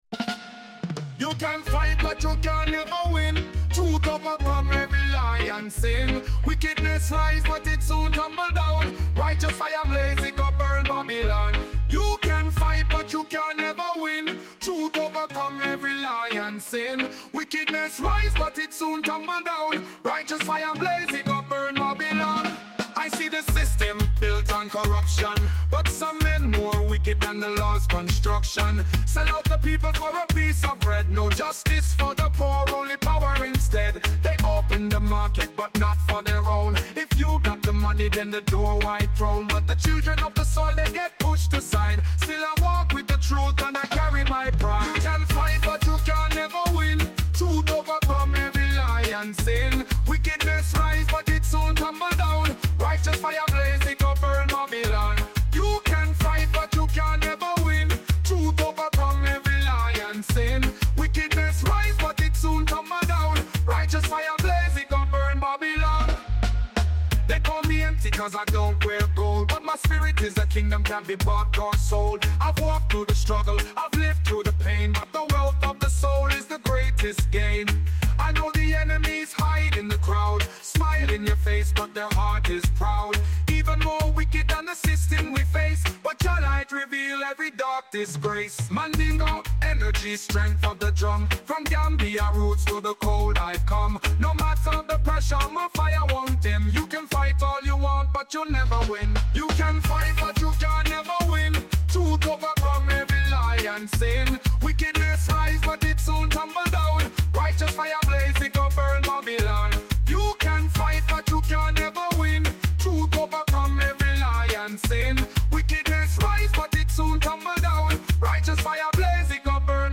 roots & culture style
Reggae • Dancehall • Conscious Vibes